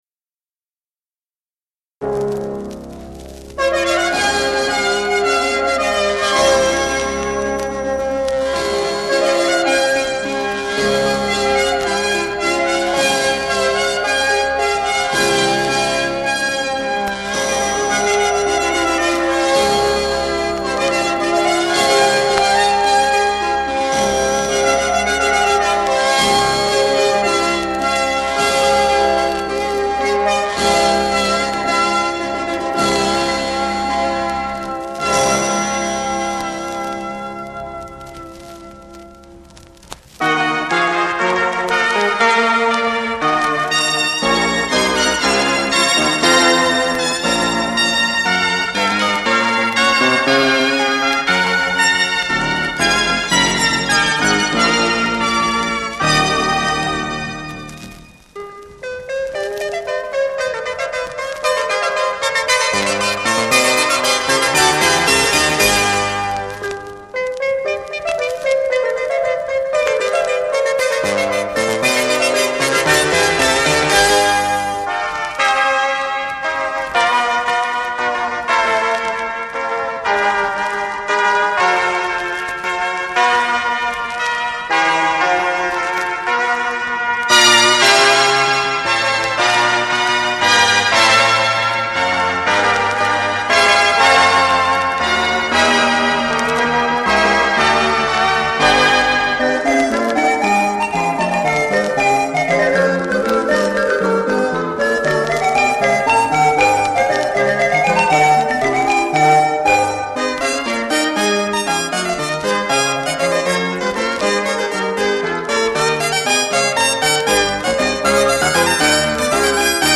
Virtuoso electronic performances